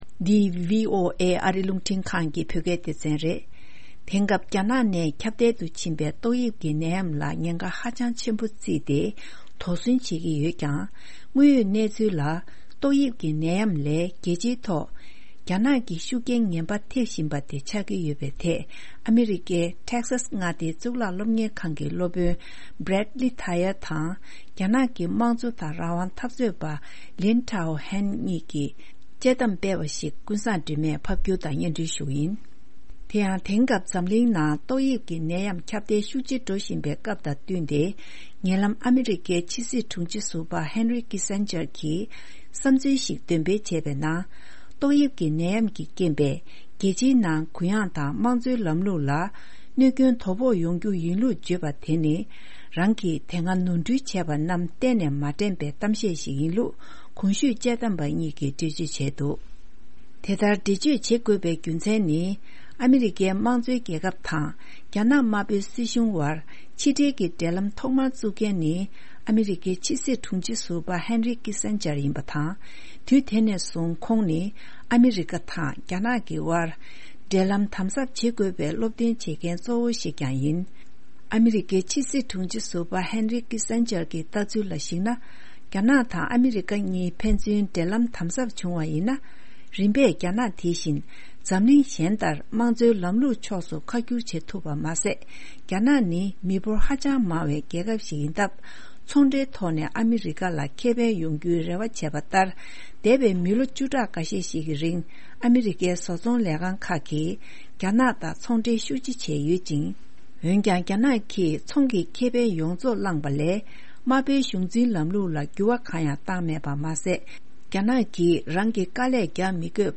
དཔྱད་གཏམ་སྤེལ་བ་ཞིག